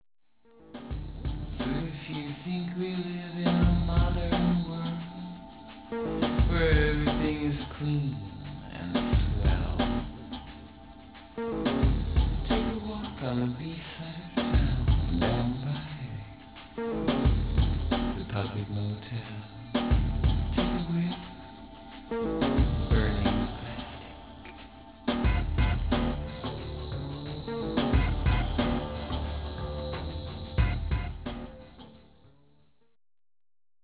vocals, keyboards, percussion
keyboards, loops, treatments
guitar
backing vocals
drums
accordion
Format: AAA/Alternative/College